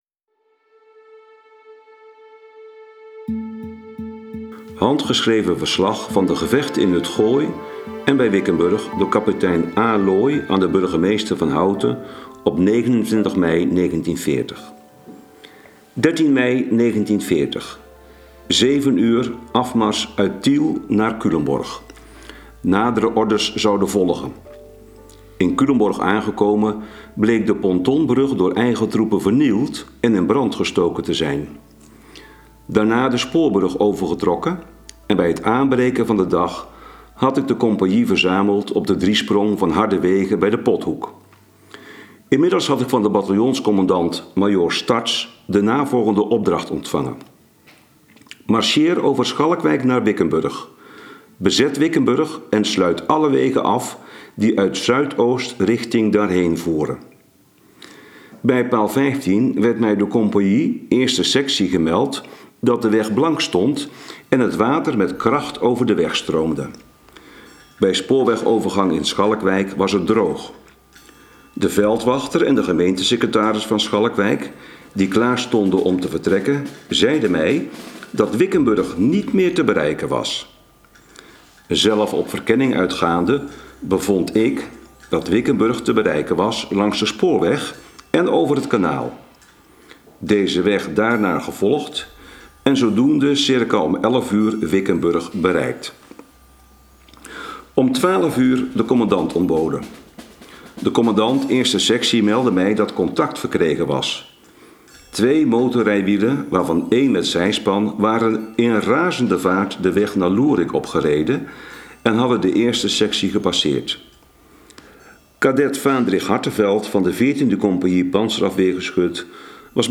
VERSLAG-GEVECHTEN-WICKENBURG.wav